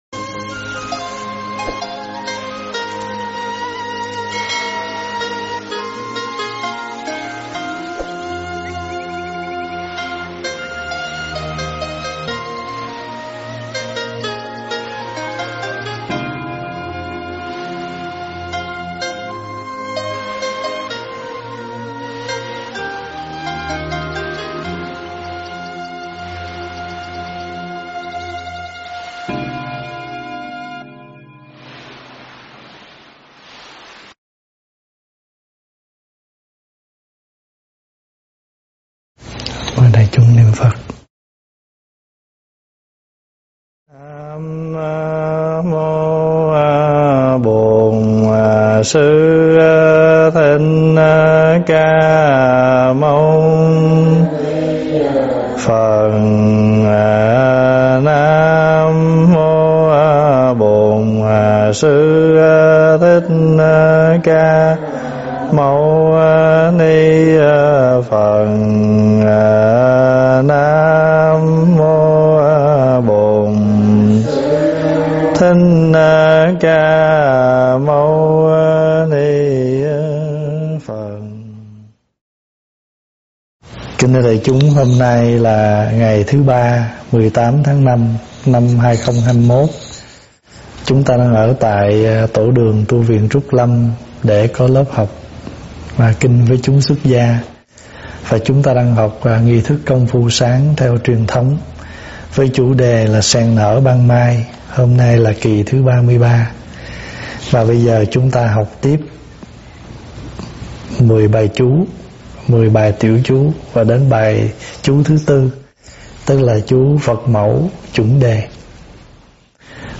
Thuyết pháp
giảng tại Tv.Trúc Lâm